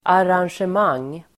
Uttal: [aransjem'ang:]